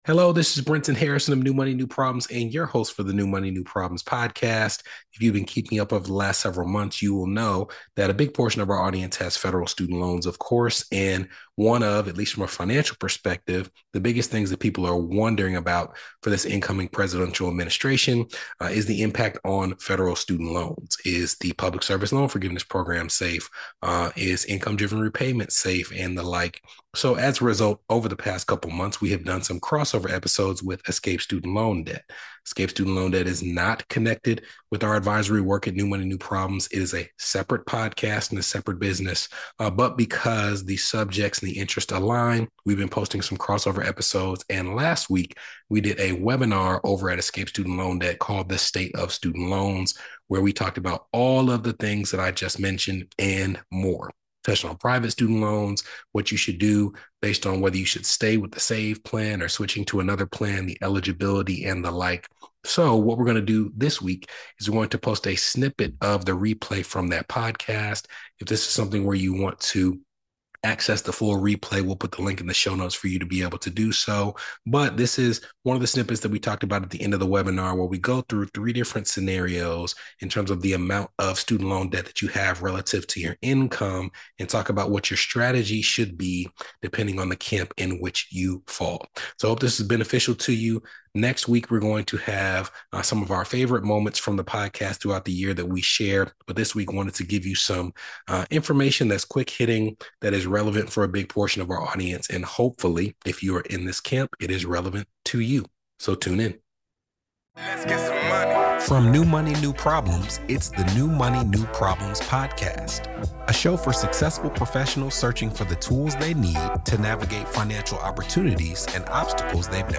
A snippet from The State of Student Loans webinar Access The Replay!